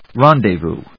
音節ren・dez・vous 発音記号・読み方
/rάːndɪvùː(米国英語), ˈrɑ:ndɪˌvu:(英国英語)/